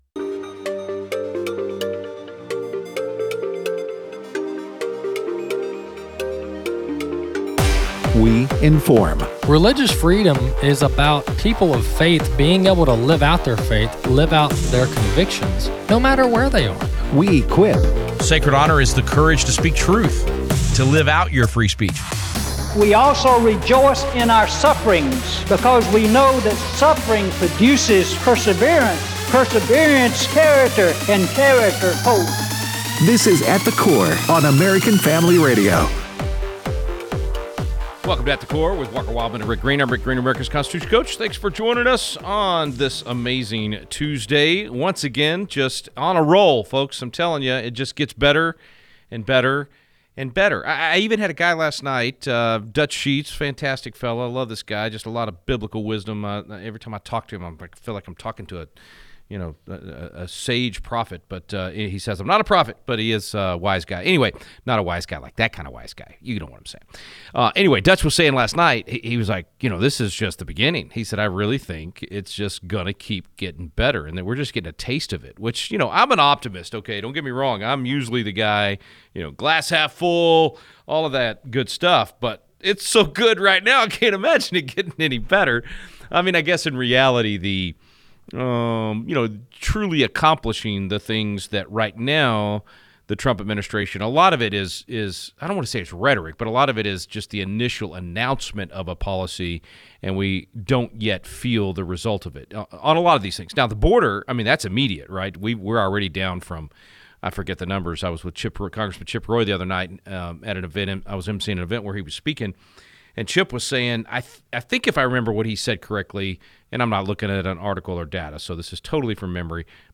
Callers share